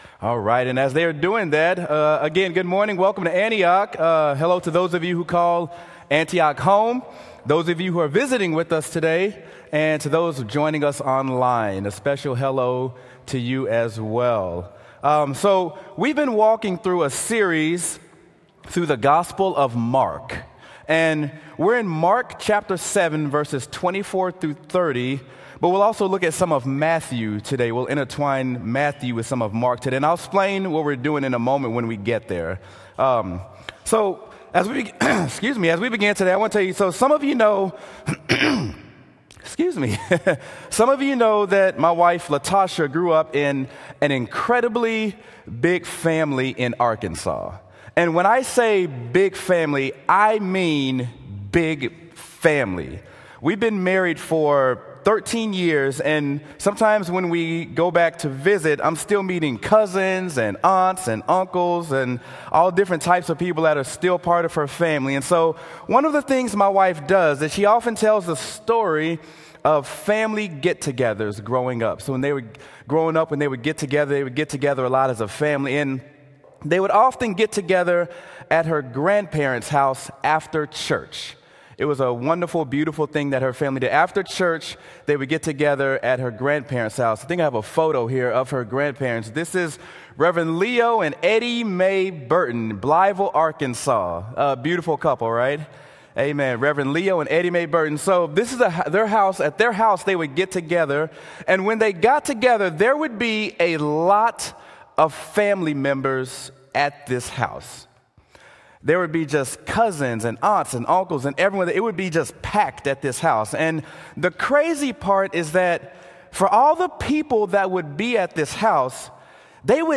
Sermon: Mark: Enough For Everyone | Antioch Community Church - Minneapolis